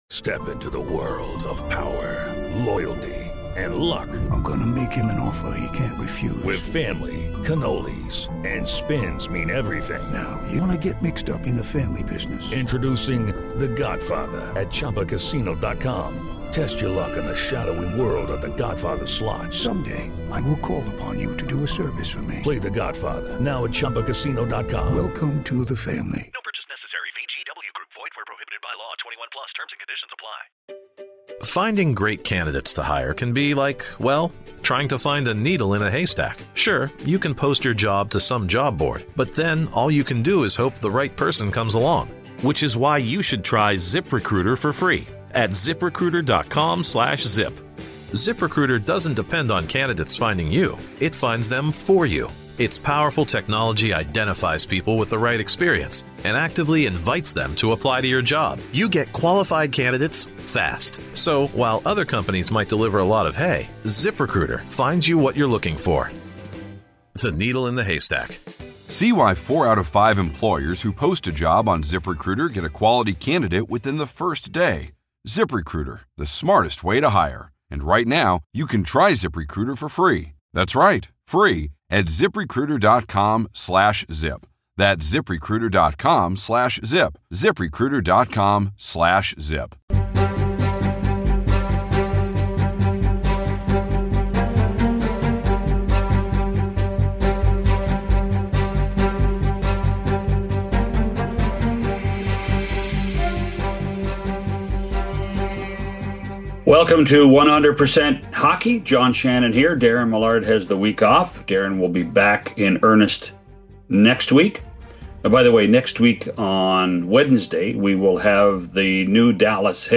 To close out the week, Mark Napier , Ben Scrivens , Jeff Beukeboom , and Matt Cooke join the show to reflect on their time in the NHL, share thoughts on their former teams, discuss future coaching ambitions, and more. A relaxed, insightful finish to a great week on the course.